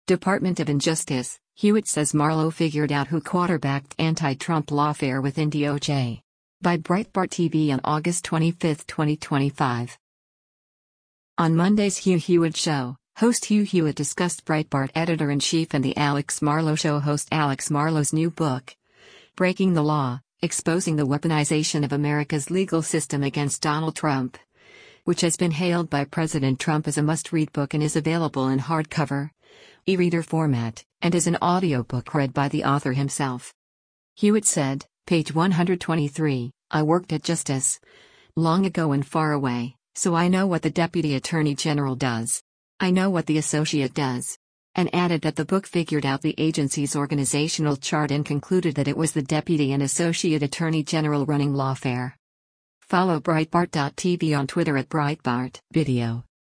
On Monday’s “Hugh Hewitt Show,” host Hugh Hewitt discussed Breitbart Editor-in-Chief and “The Alex Marlow Show” host Alex Marlow’s new book, Breaking the Law: Exposing the Weaponization of America’s Legal System Against Donald Trump, which has been hailed by President Trump as a “must read” book and is available in hardcovereReader format, and as an audiobook read by the author himself.